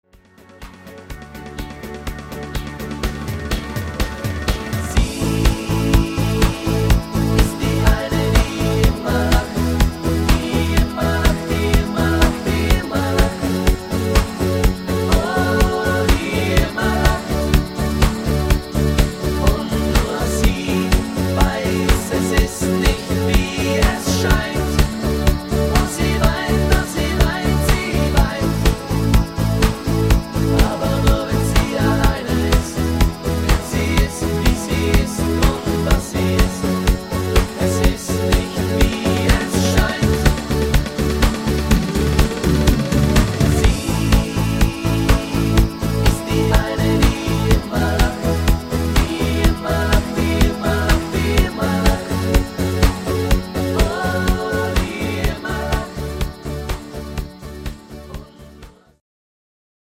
Party-Discofox